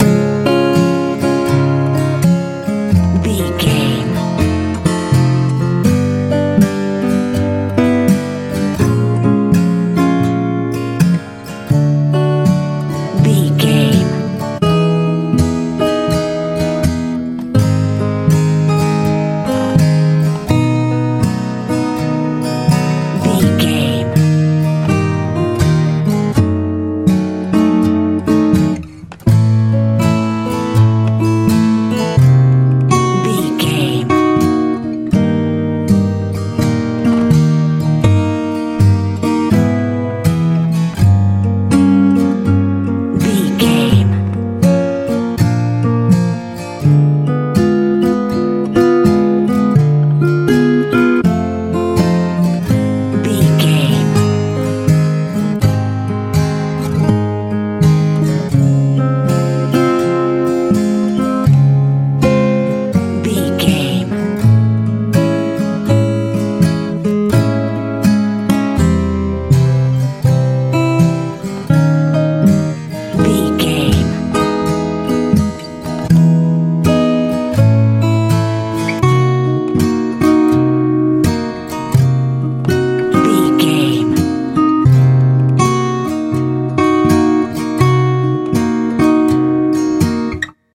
lite pop feel
Ionian/Major
C♯
joyful
soft
acoustic guitar
smooth
soothing
relaxed
mellow
melancholy